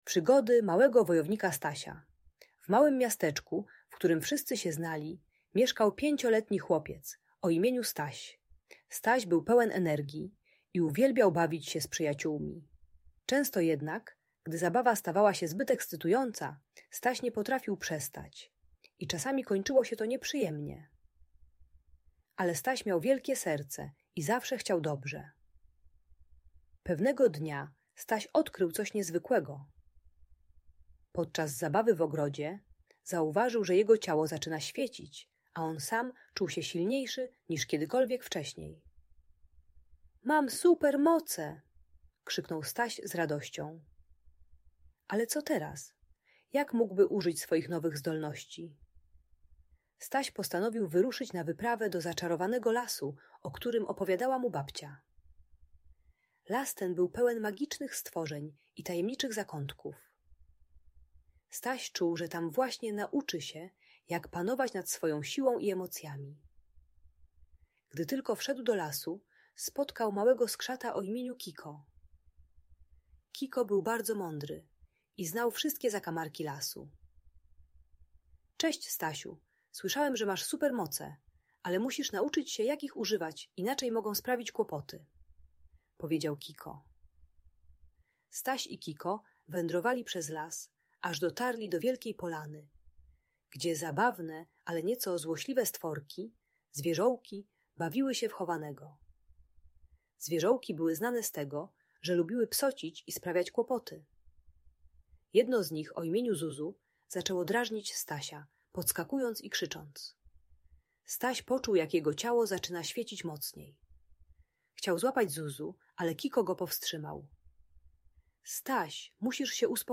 Historia Stasia uczy techniki głębokiego oddechu i zatrzymania się przed reakcją, gdy emocje narastają. Audiobajka o panowaniu nad złością i nadmierną energią dla przedszkolaków.